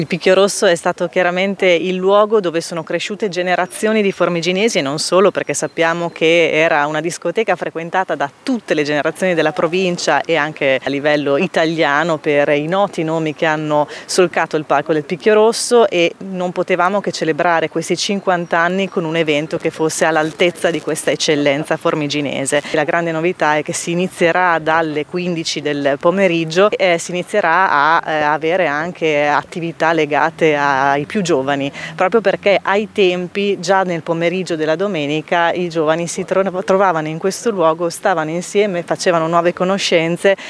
La sindaca di Formigine Elisa Parenti